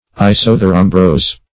Search Result for " isotherombrose" : The Collaborative International Dictionary of English v.0.48: Isotherombrose \I*soth`er*om"brose\, n. [Iso- + Gr. qe`ros summer + 'o`mbros rain.]